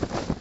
flagflap.wav